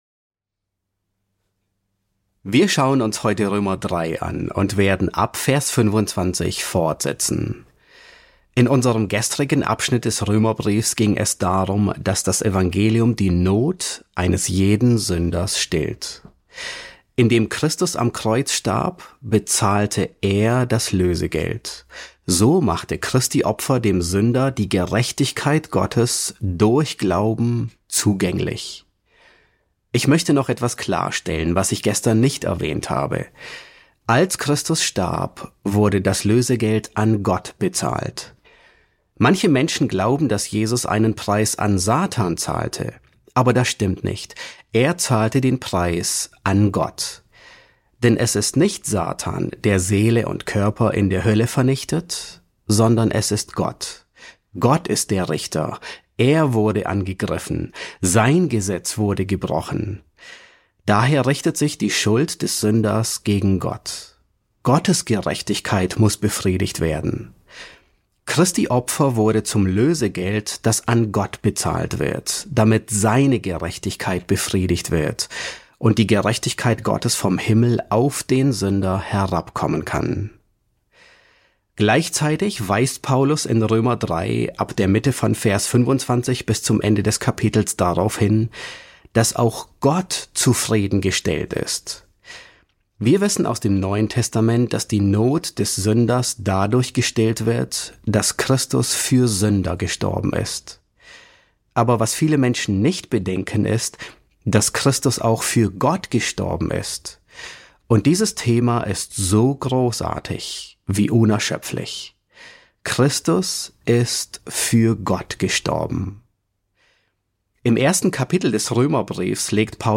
S8 F3 | Das Evangelium erfüllt Gottes Forderungen ~ John MacArthur Predigten auf Deutsch Podcast